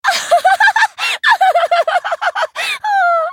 Risada Lux (LoL)
Risadinha da personagem Lux de League Of Legends (LoL), também conhecida como a Dama Da Luz.
risada-lux-lol.mp3